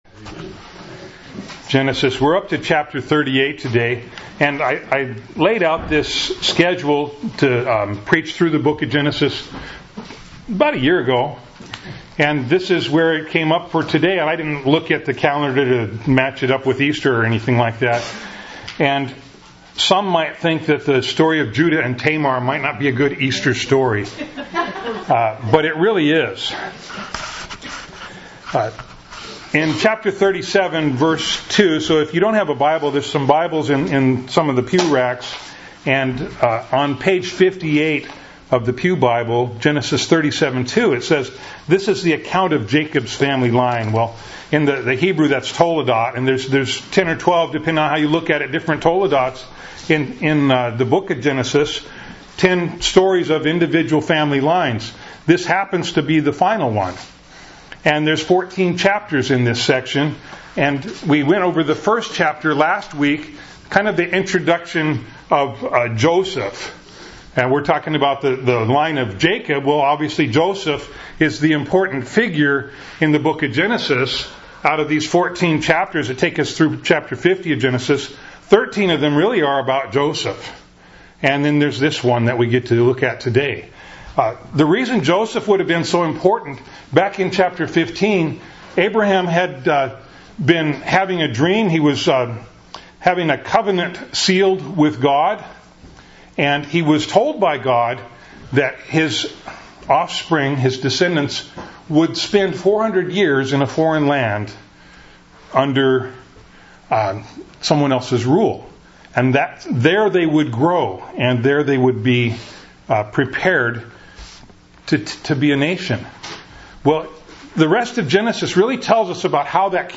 Bible Text: Genesis 38:1-30 | Preacher